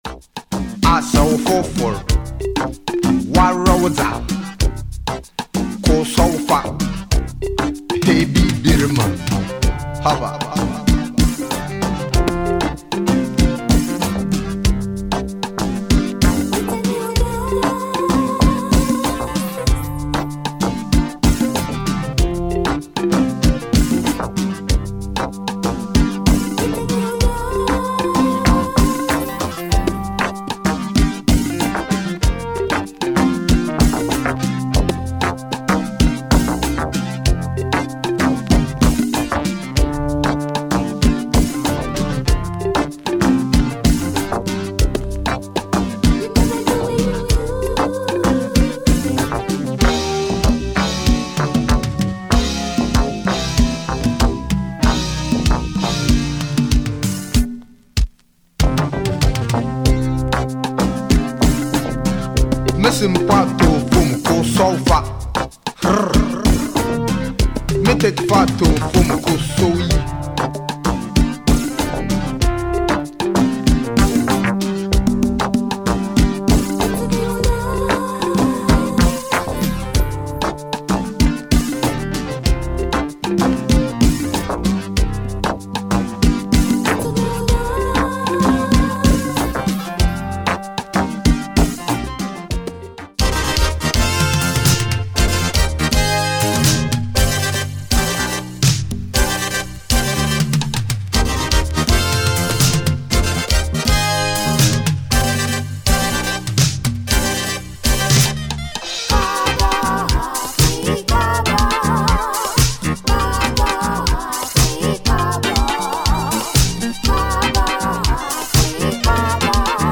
drums
synths